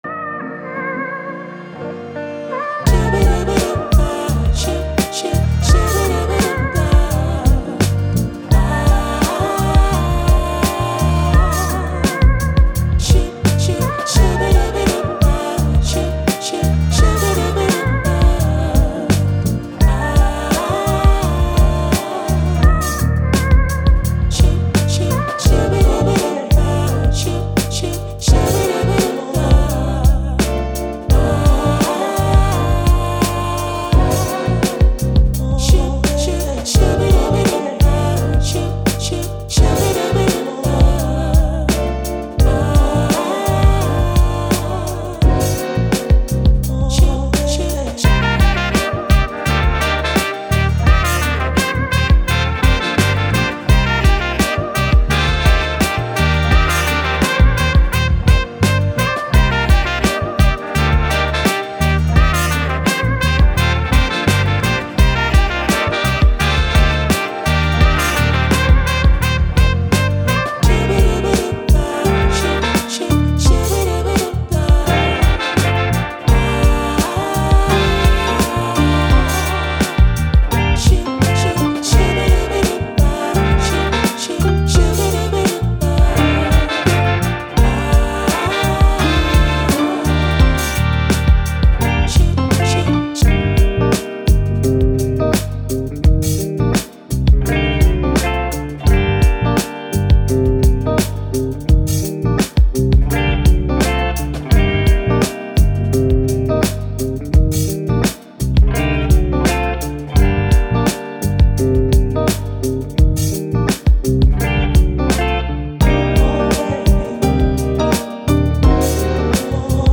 Soul, Hip Hop, Elegant, Vintage, Brass